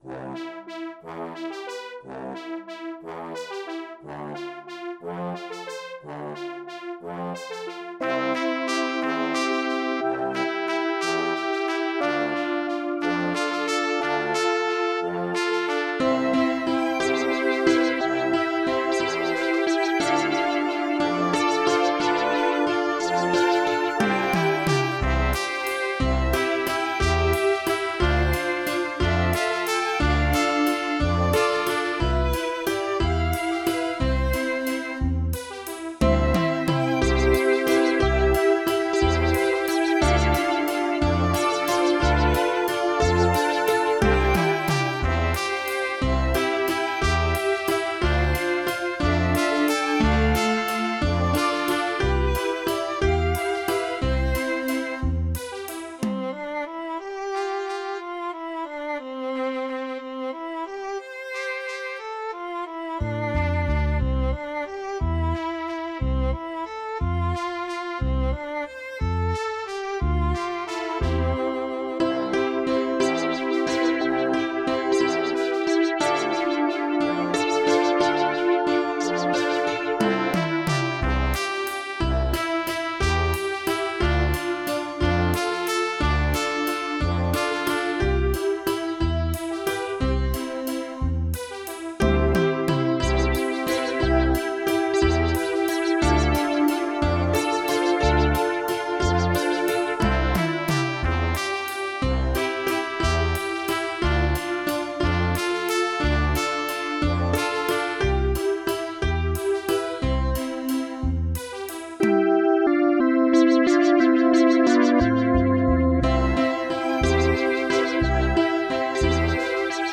с помощью компьютера и синтезатора
Инструмент. версия